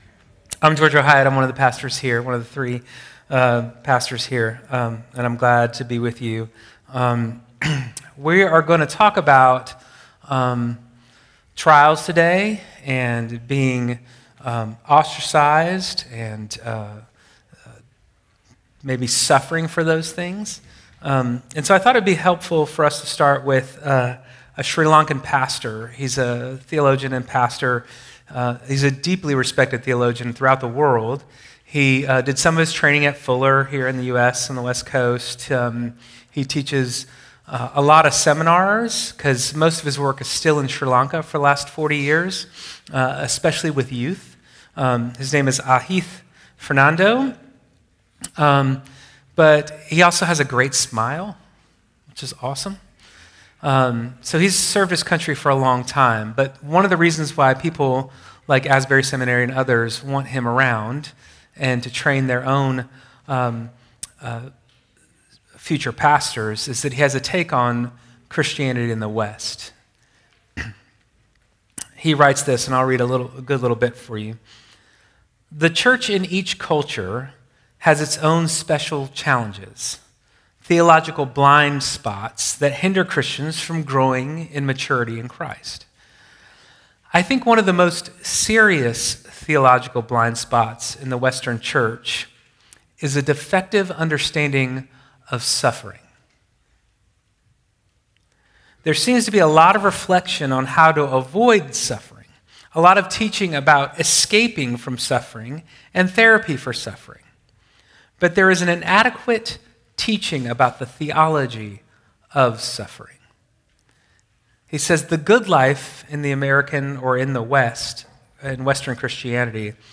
11.23 sermon - Made with Clipchamp.m4a